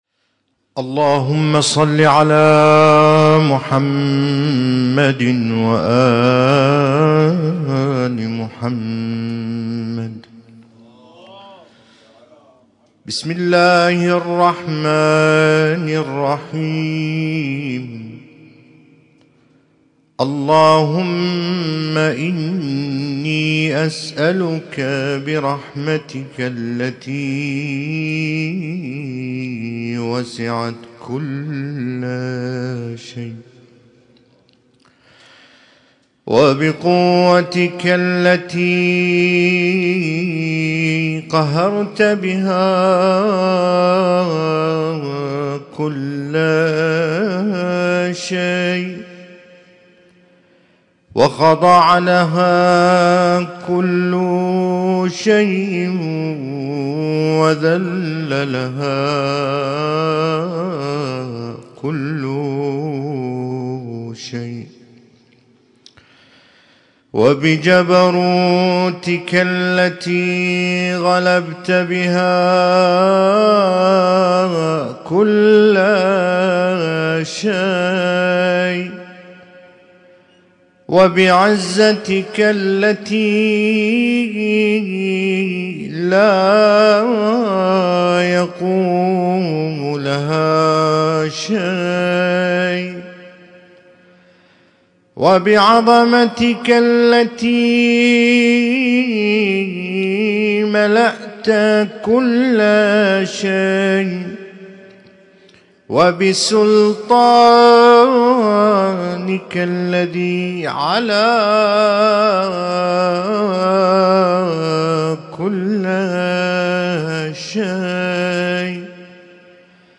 Husainyt Alnoor Rumaithiya Kuwait
اسم التصنيف: المـكتبة الصــوتيه >> الادعية >> دعاء كميل